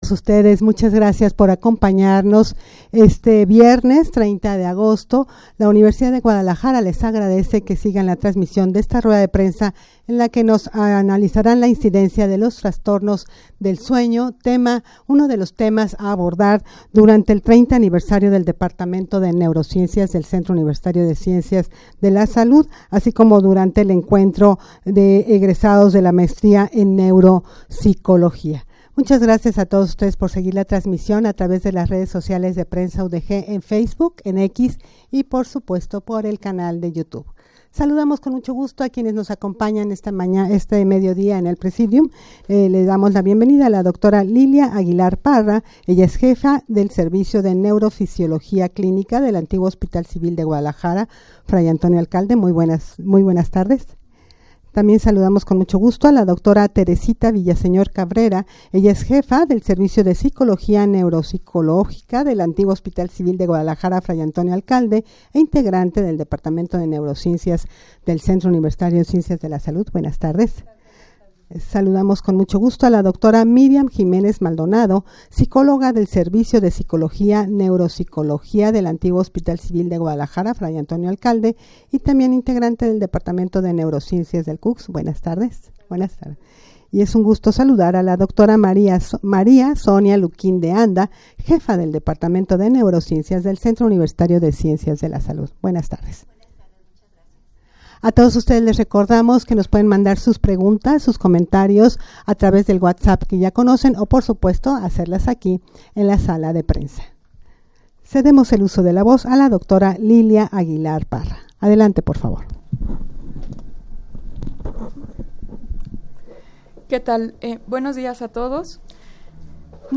Audio de la Rueda de Prensa
rueda-de-prensa-para-analizar-la-incidencia-de-los-trastornos-del-sueno.mp3